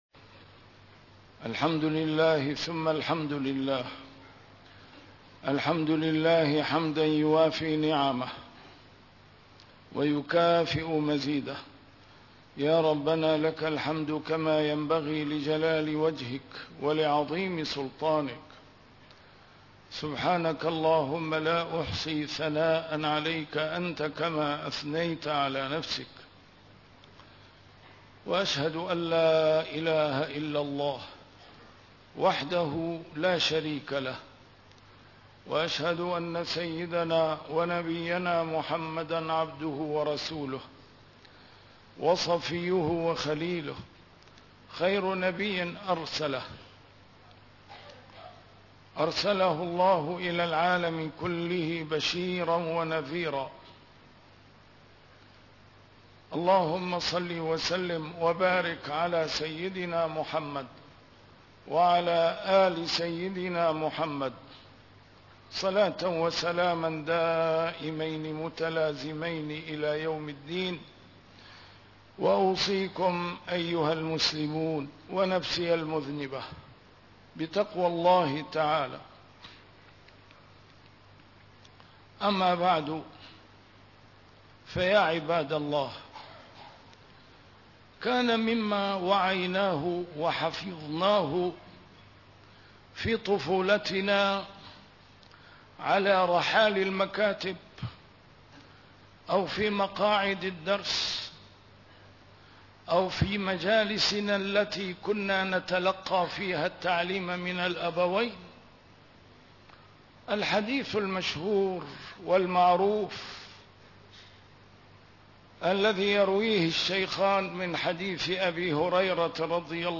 A MARTYR SCHOLAR: IMAM MUHAMMAD SAEED RAMADAN AL-BOUTI - الخطب - الخروج من كوارث الأمة هل هذا سبيله؟